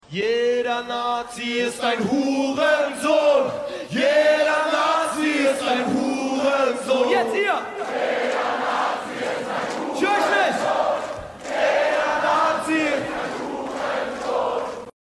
Last few days, it has become trendy on American TikTok to show opposition to the rise of fascism in America by using a short musical phrase in German language that I assume is or was a stadium chant.